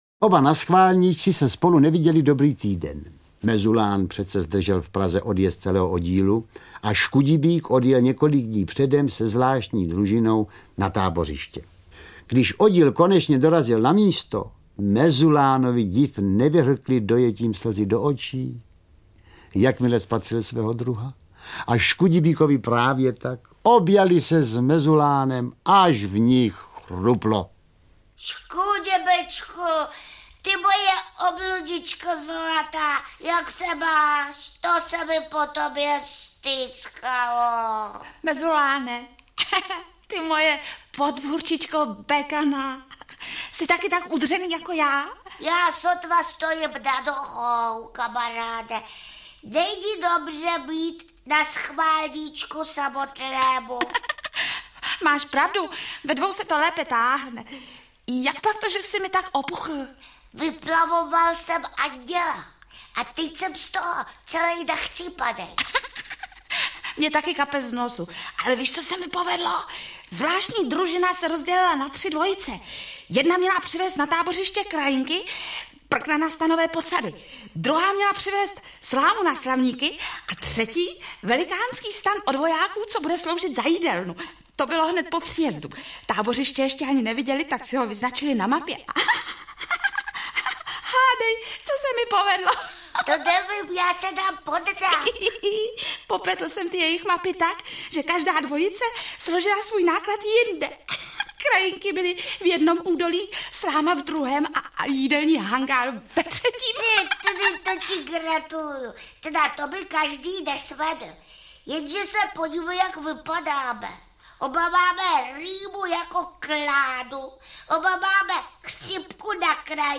František Nepil: Dětem audiokniha
Ukázka z knihy